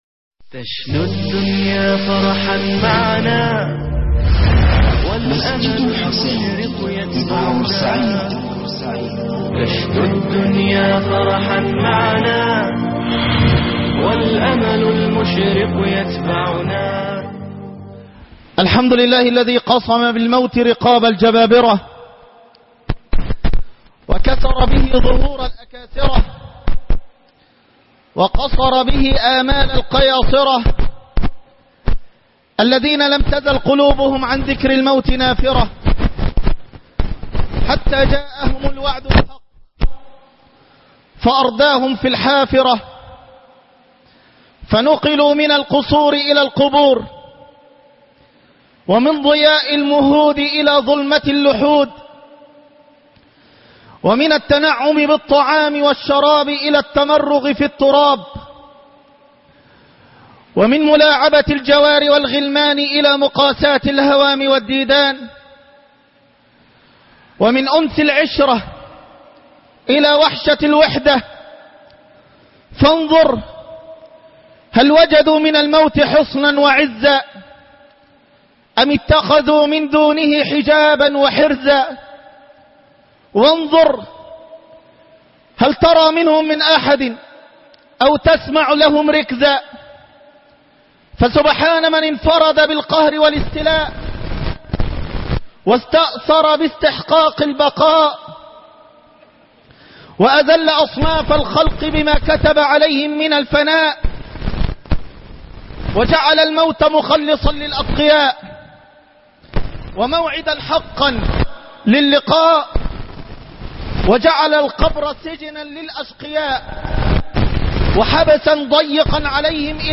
بيتك الحقيقي- خطب الجمعة